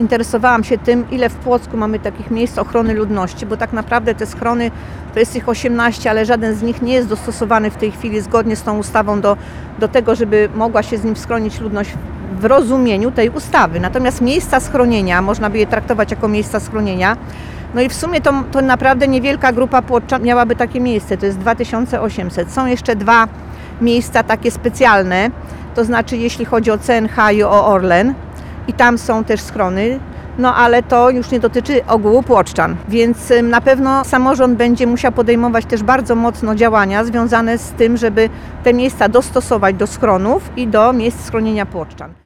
Konferencja przed płockim ratuszem
– dodaje posłanka Gapińska.